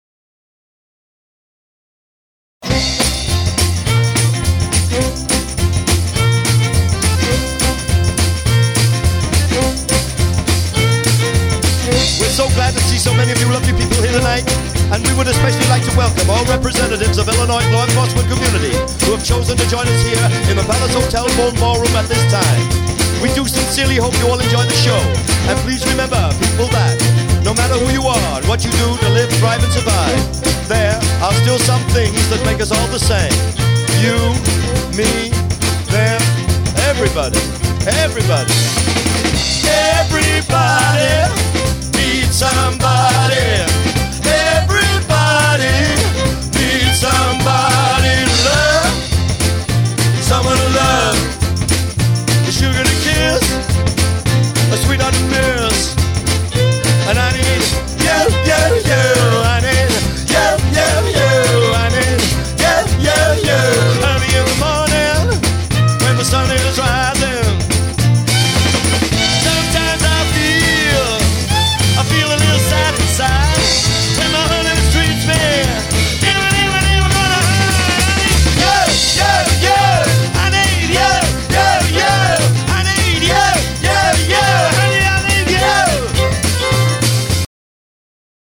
SOUL/R n’B